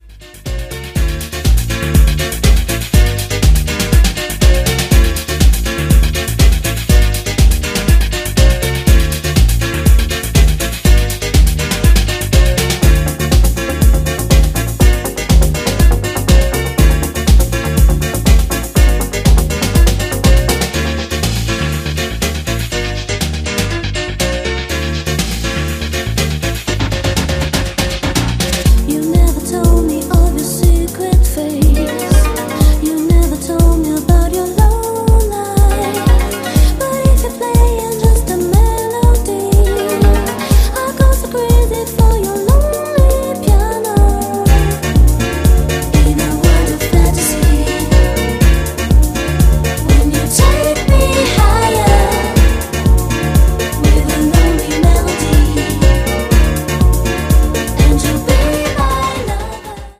Stone-cold deep house from Italy, straight from 1991!